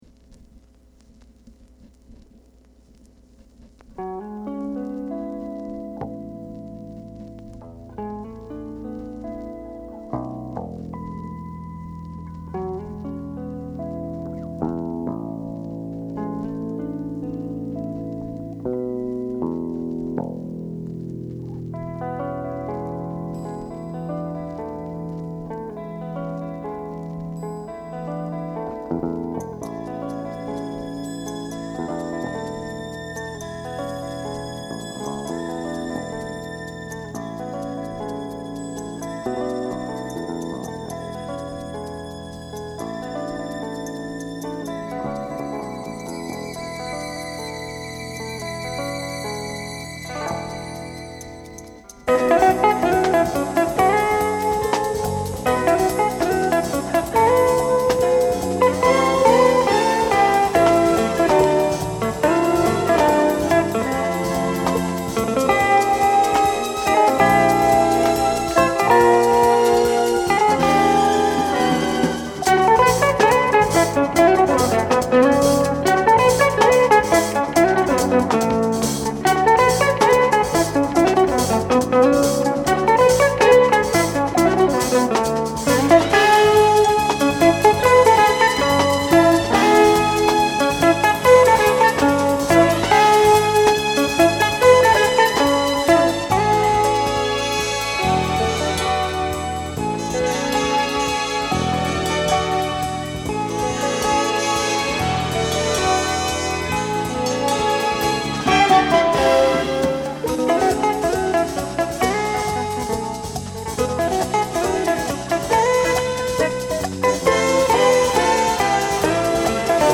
Genre: Jazz Fusion
東京・芝浦スタジオにて1977年10月に録音。
エレクトリックピアノ
ベース
ドラムス
テナーサックス
ふたりのギターが交互に、そして重なり合いながら疾走する。